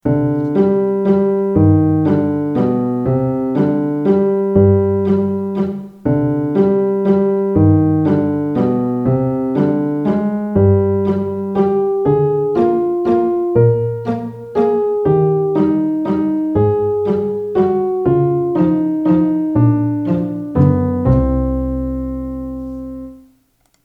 theFlyingBat_mellow.wav